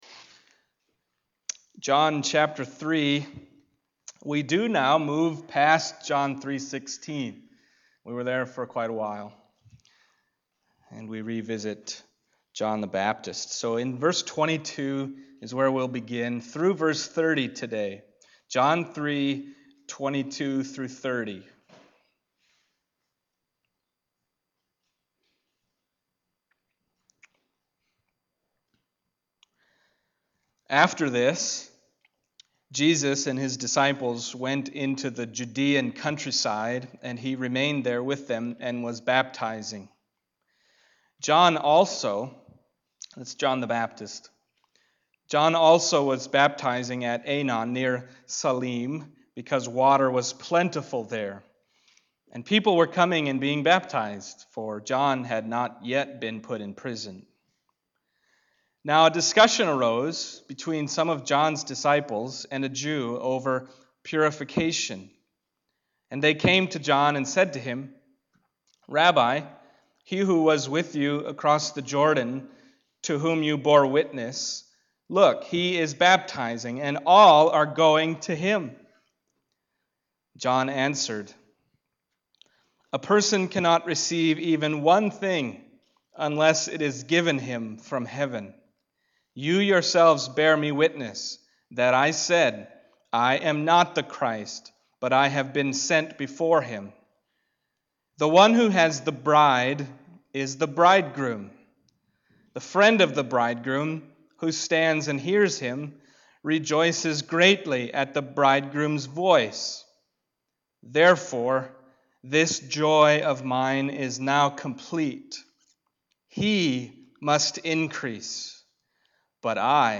Passage: John 3:22-30 Service Type: Sunday Morning